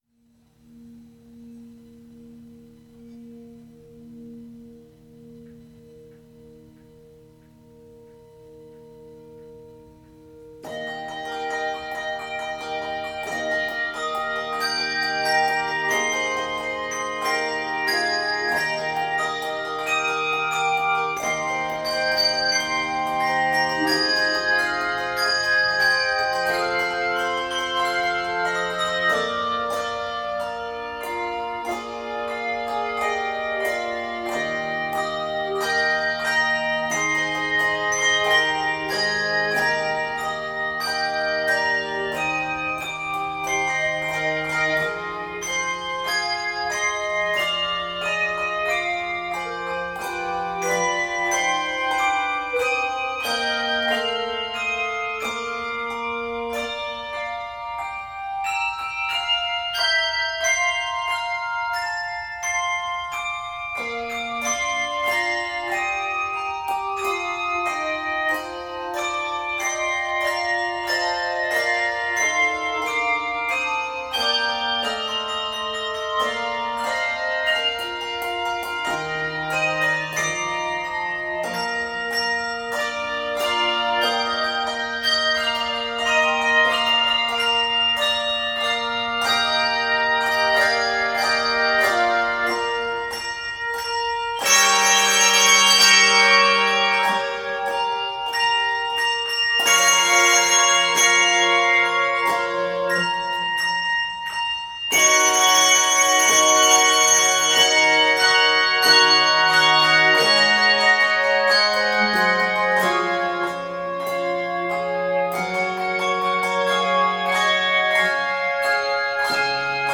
Key of Eb Major.
Octaves: 3-5